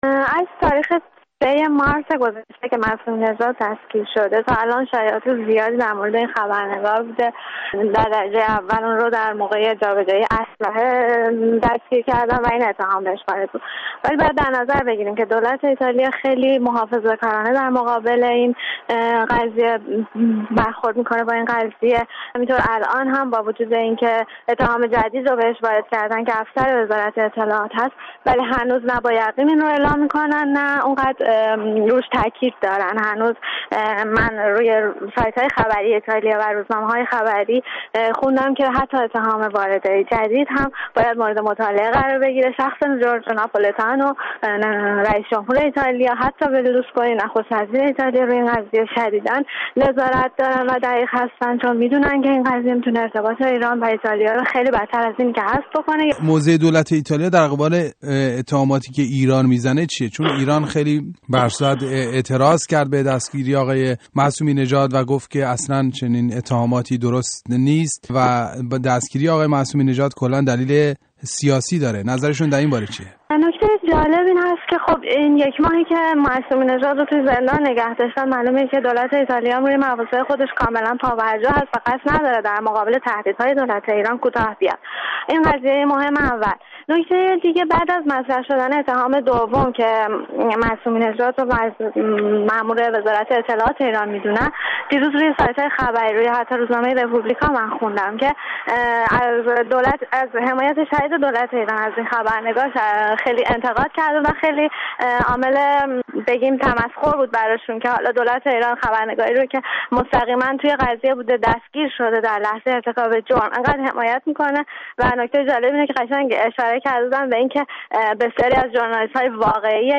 گفت و گوی